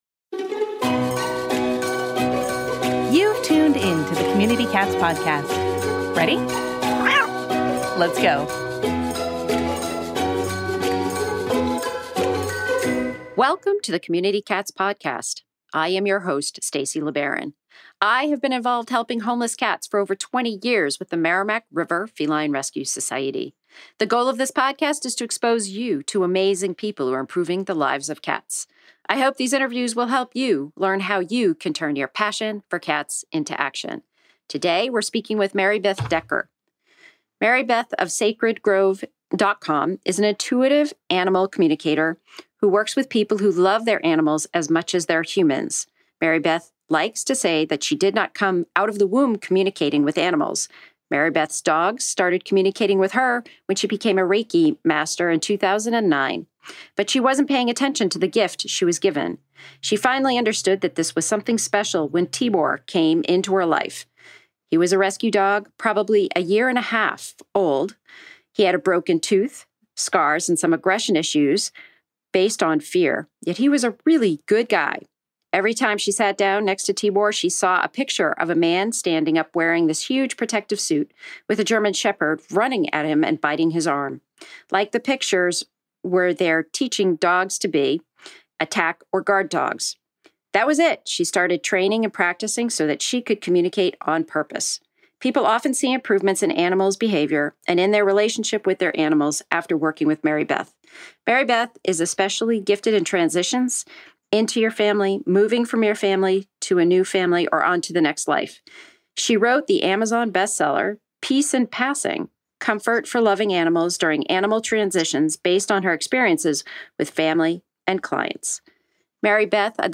Interview!